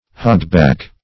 Hogback \Hog"back`\, n.